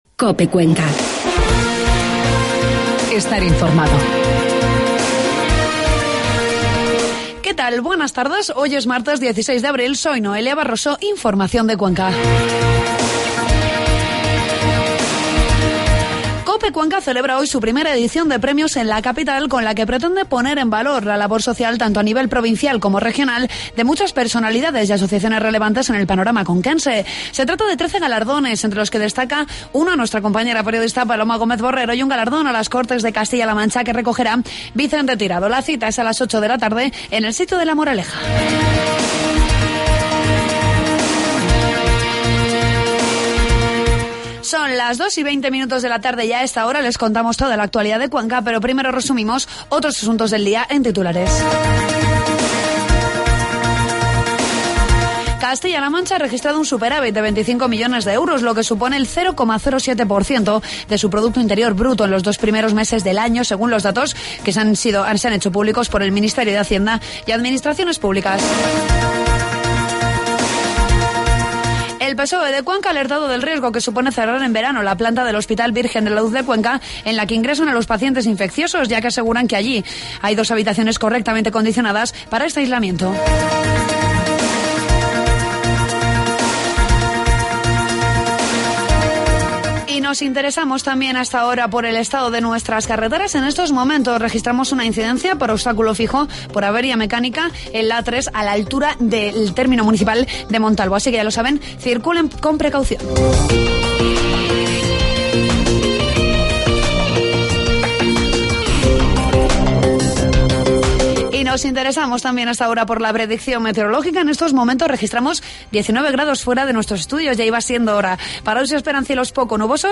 informativos de mediodía